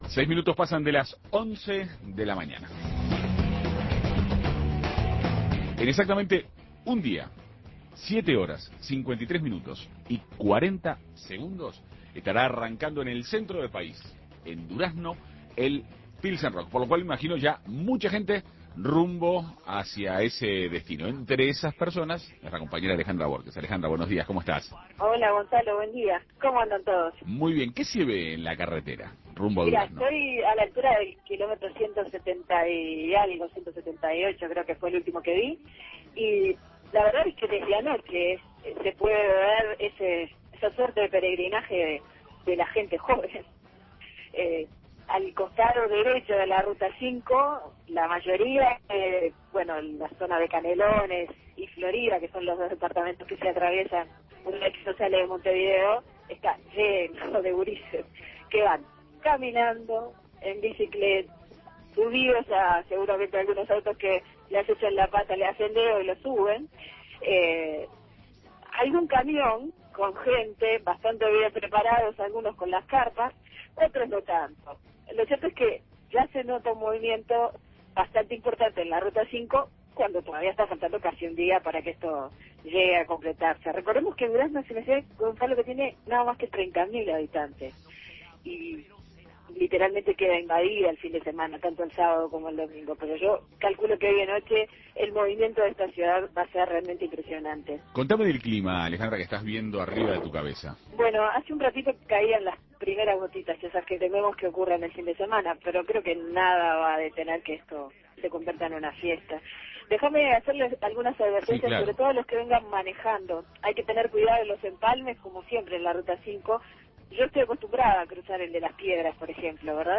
Crónica
entrevista